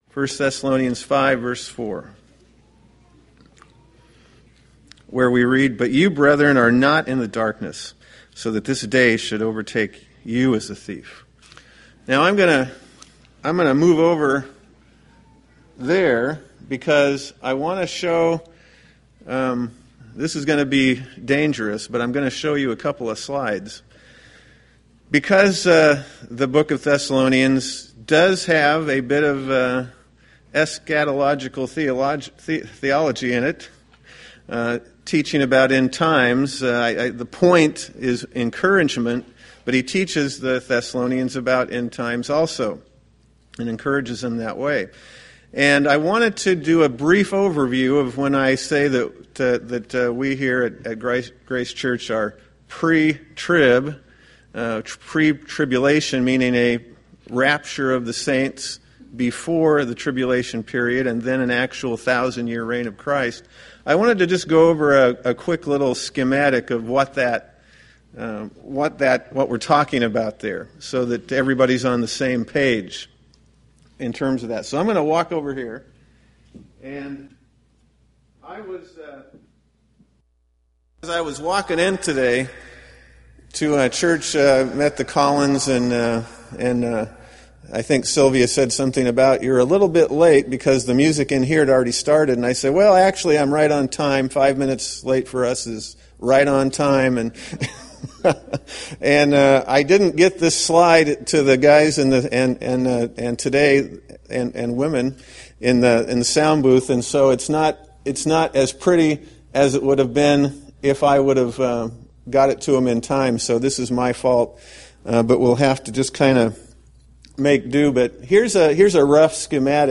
1 Thessalonians Sermon Series